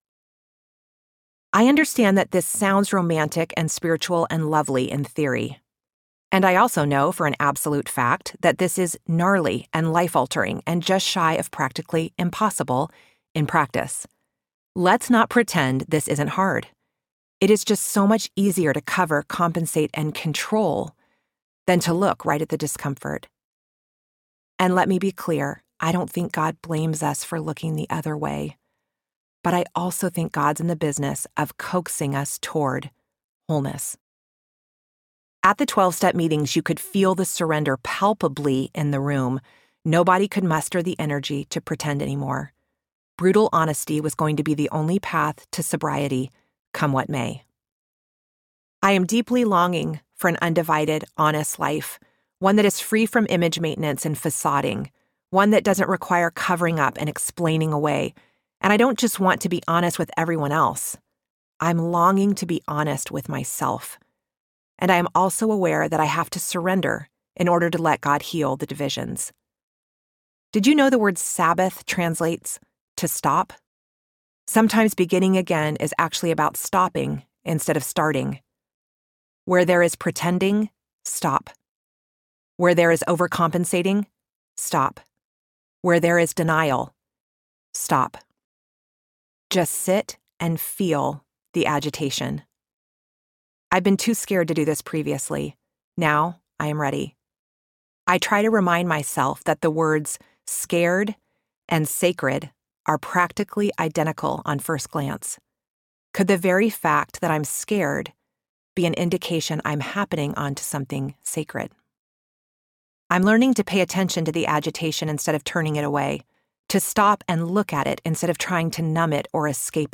Begin Again Audiobook